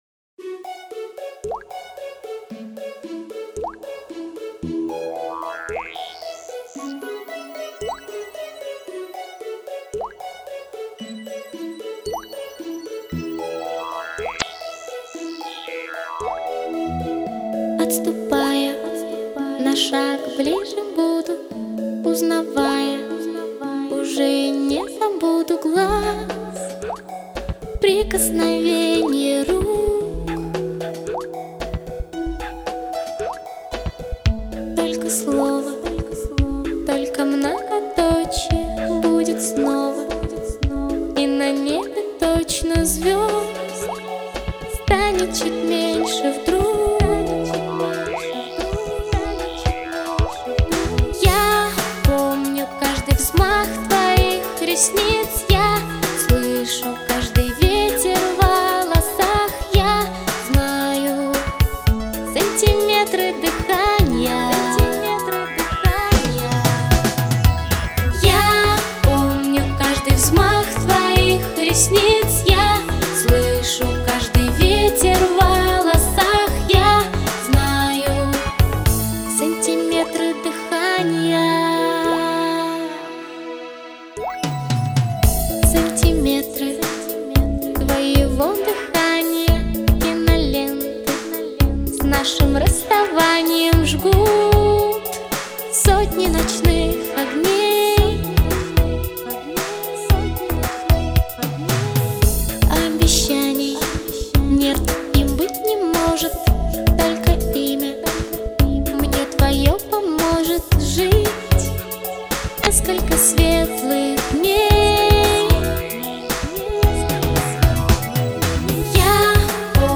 Жанр: Поп Продолжительность: 00:47:21 Список композиций: